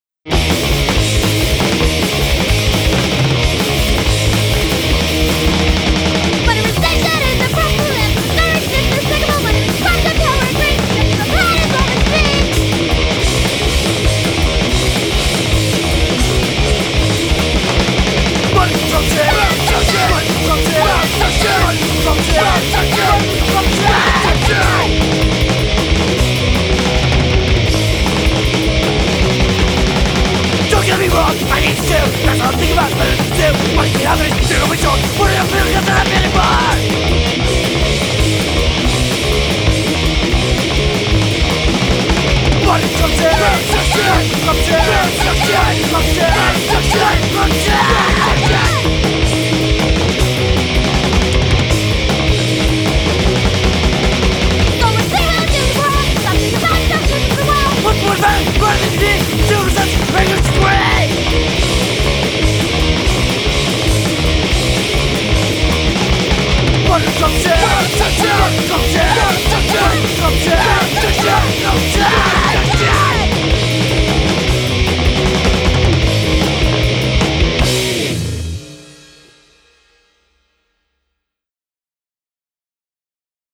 punk rock See all items with this value